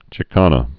(chĭ-känə, shĭ-)